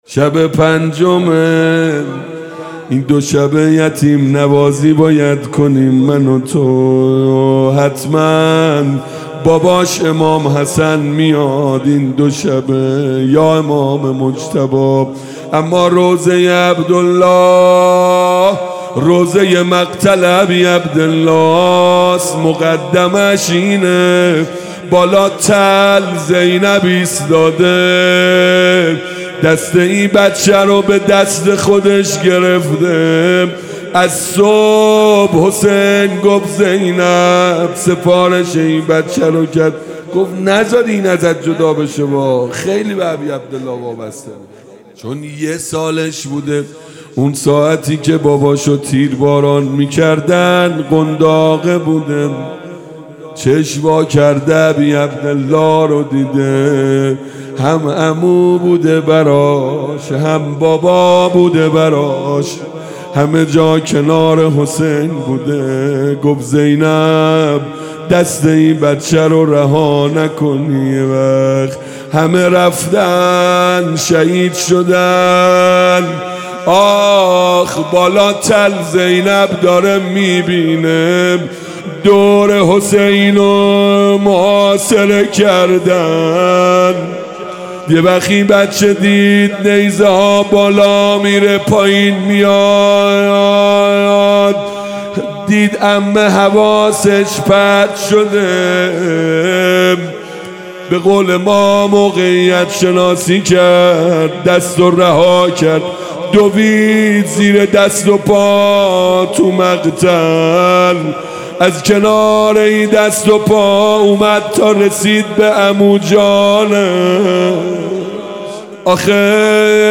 شب پنجم محرم 97 - روضه